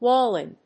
/ˈwɔlɪn(米国英語), ˈwɔ:lɪn(英国英語)/